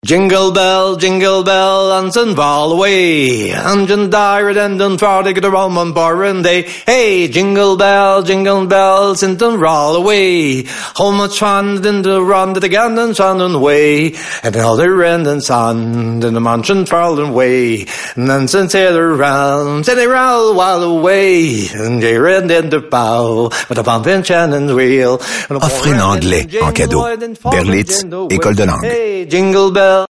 SilverRadio - Campaign